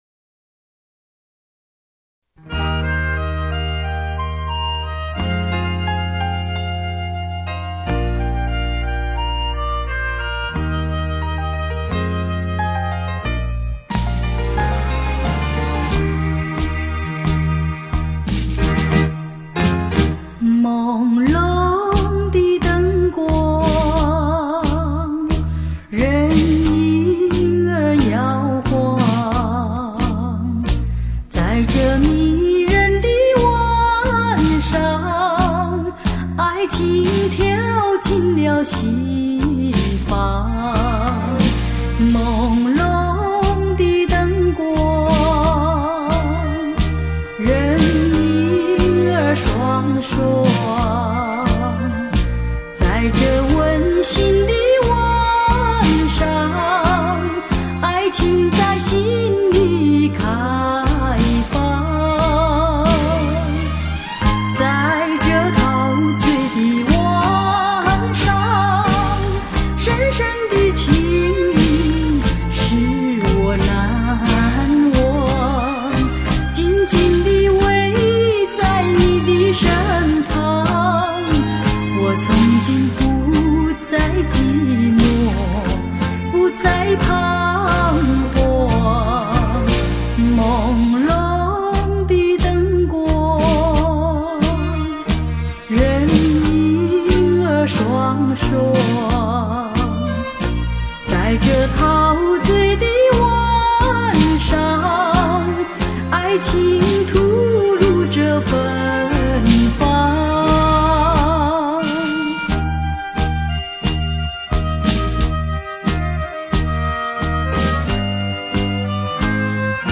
此专辑鲜为人知，特点大部分是探戈节奏比较珍贵